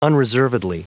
Prononciation du mot unreservedly en anglais (fichier audio)
Prononciation du mot : unreservedly